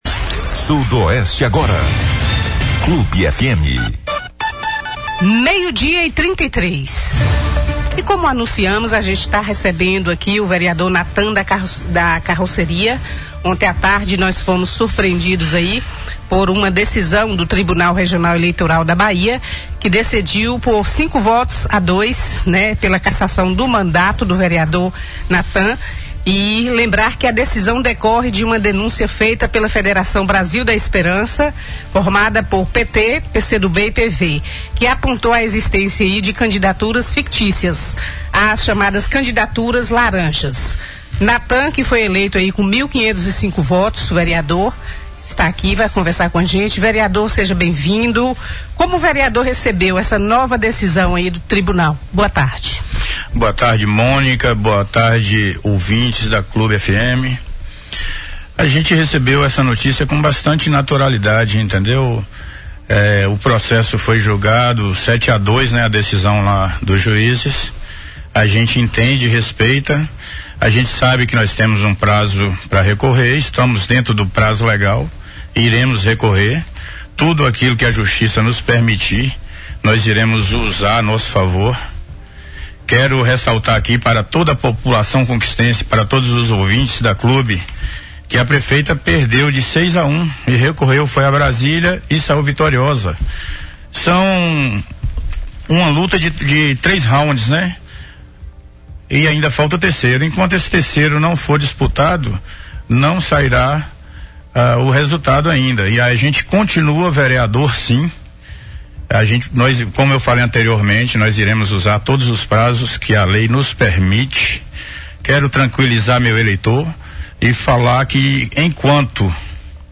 Em entrevista à Rádio Clube de Conquista, repercutida pelo BLOG DO ANDERSON, Natan afirmou que recebeu a decisão com tranquilidade e que irá recorrer até a última instância.